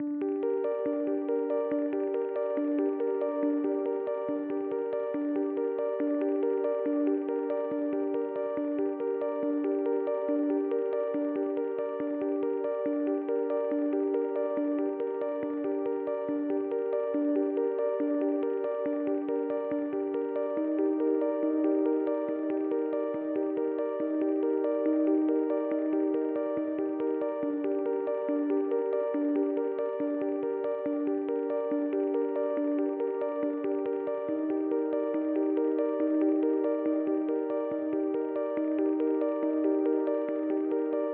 暧昧的Trippy Chill Bells旋律
添加了混响轻微的回声/延时。
Tag: 140 bpm Trap Loops Bells Loops 6.92 MB wav Key : D Logic Pro